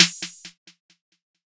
TUPAC MURDER CONFESSION SNARE.wav